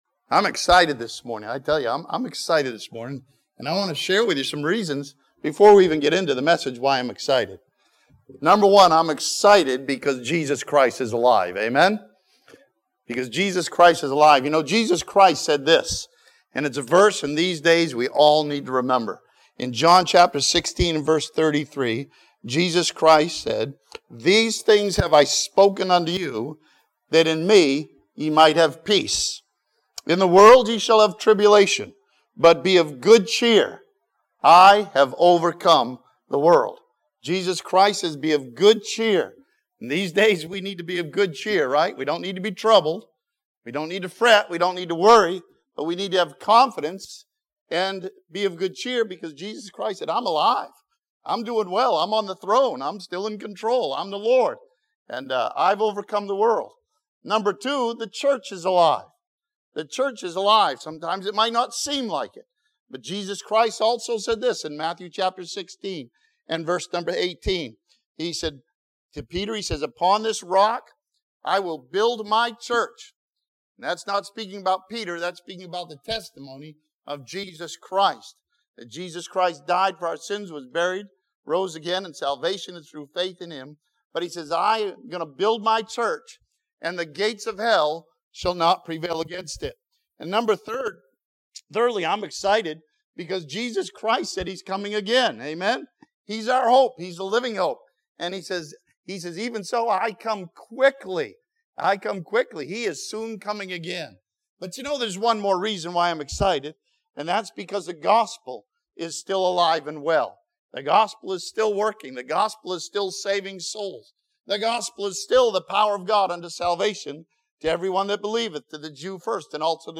This sermon from John chapter one studies John the Baptist and His miraculous birth and power to proclaim Jesus Christ.